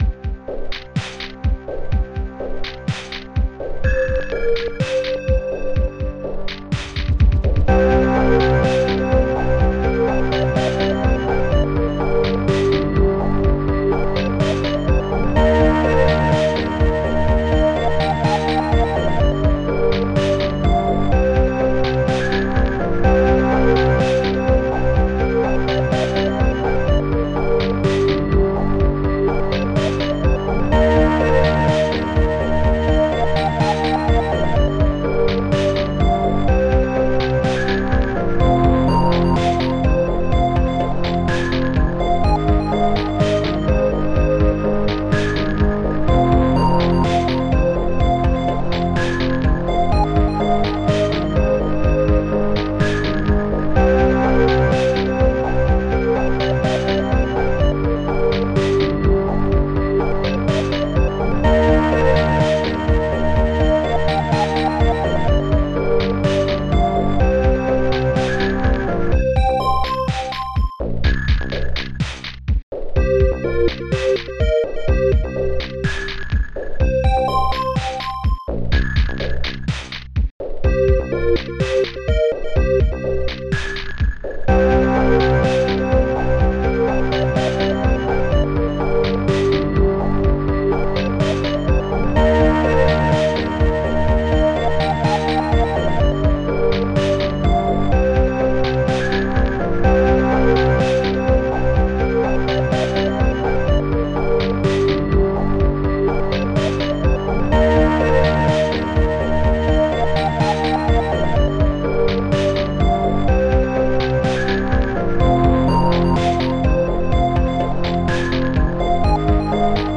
Tracker AMOS Music Bank
Instruments st-01:arztbass st-02:typewriter st-01:drop2 st-02:spliffclap st-01:digdug st-02:guitar7 st-01:church st-01:sinecz st-02:sonar
Mellow.mp3